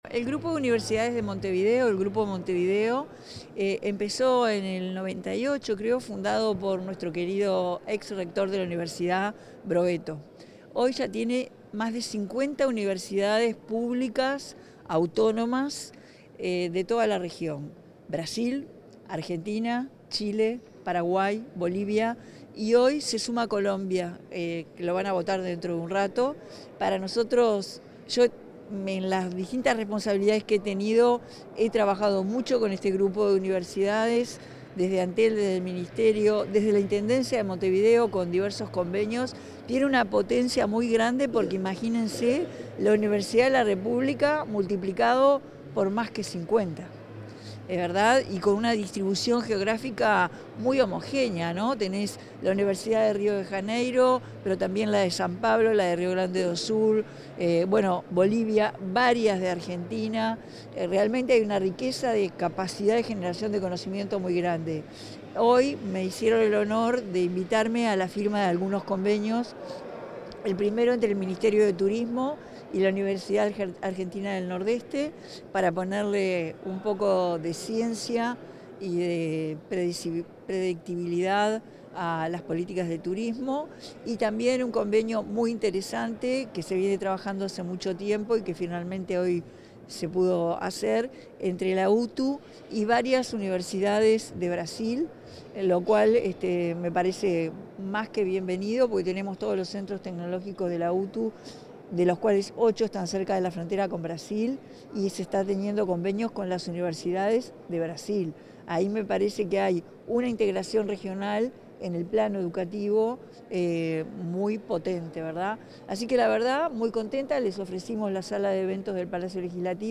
Declaraciones de la presidenta de la República en ejercicio, Carolina Cosse
Tras participar, en el Palacio Legislativo, en el acto de firma de convenios entre instituciones públicas de enseñanza de Uruguay y la región, la